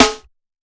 TIGHT_SNARE.wav